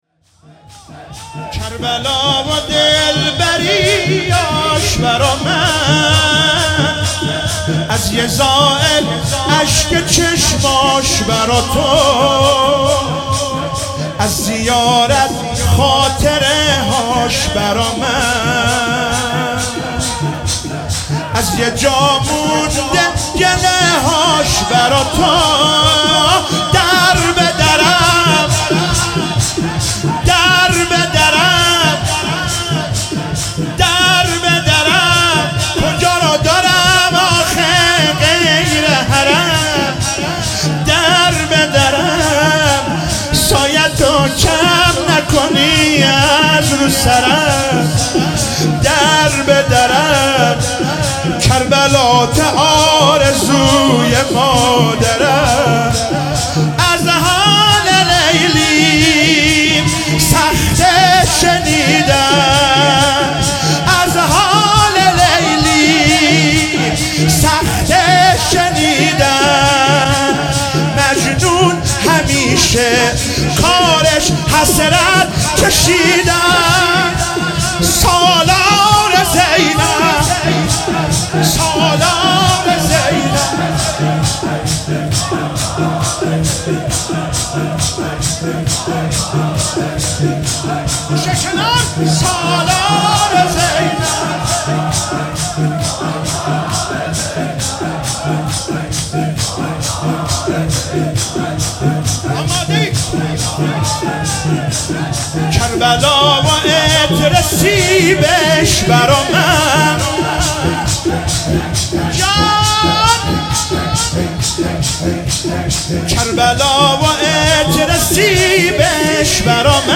شب سوم محرم96 - شور -کربلا و دلبریاش برا من